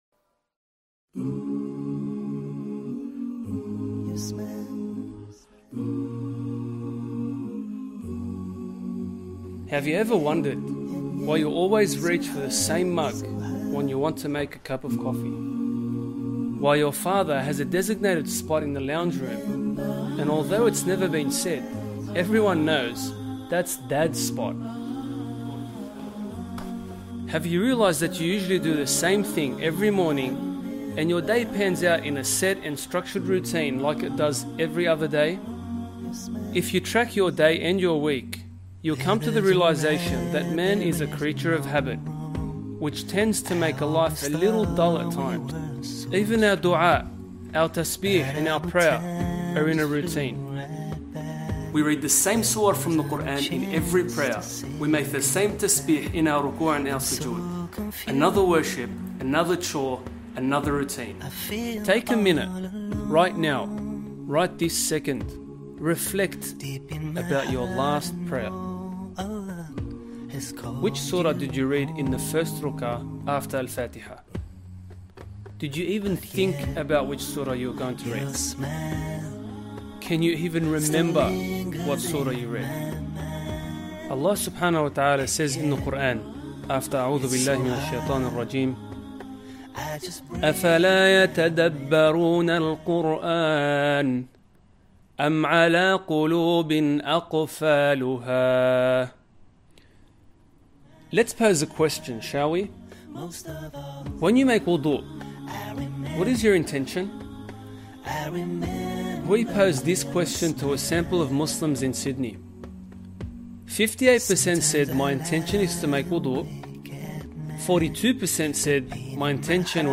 The DVD is professionally filmed, directed and produced, offering viewers high quality educational content.